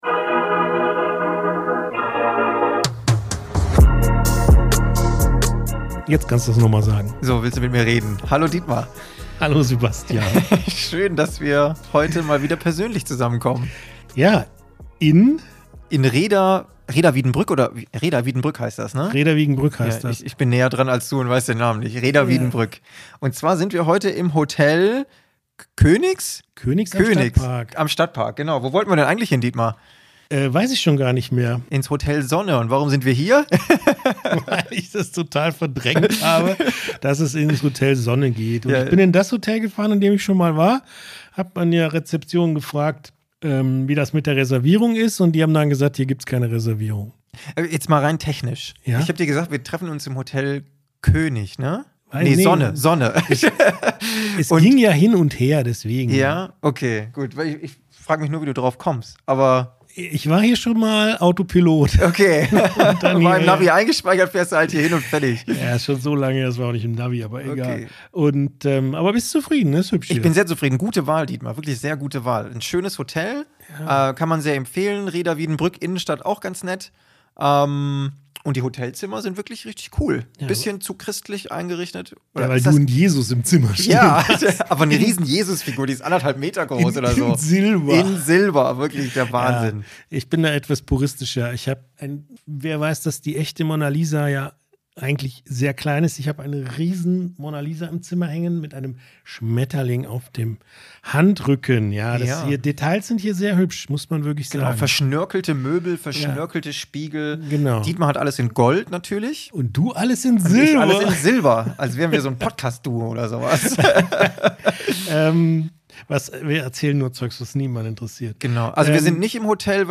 Das Ganze findet dem Anlass entsprechend in einem Hotel statt.